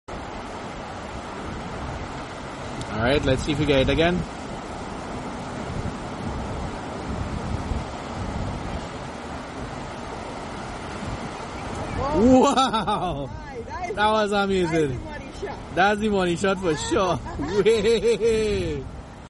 Chip chip magic on Mayaro Beach, Trinidad & Tobago, West Indies.